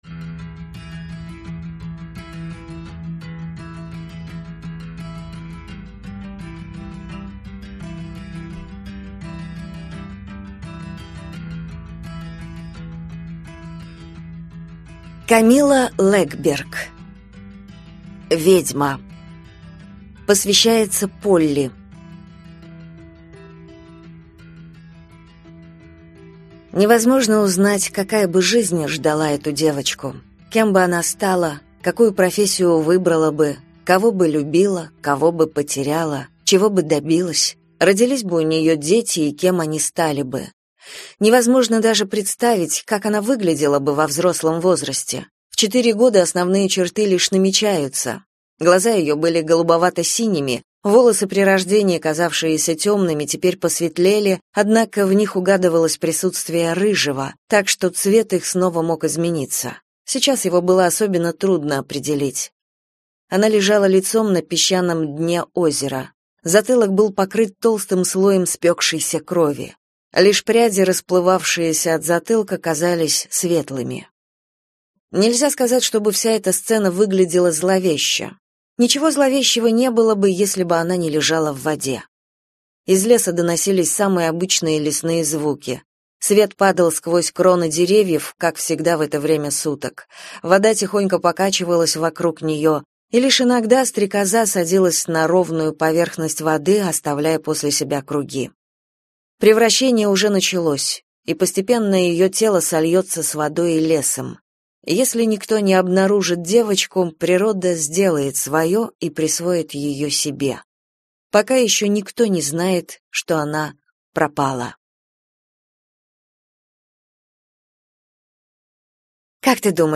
Аудиокнига Ведьма. Часть 1 | Библиотека аудиокниг